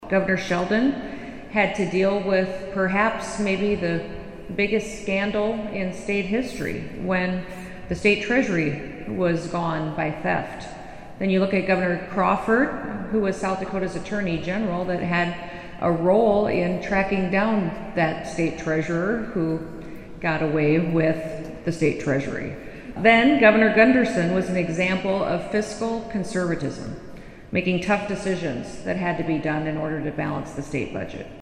Bronze statues of South Dakota Govs. Charles Sheldon (2), Coe Crawford (6) and Carl Gunderson (11) were unveiled at the State Capitol in Pierre this morning (June 11).
Gov. Kristi Noem reflected on some of the challenges overcome by her predecessors.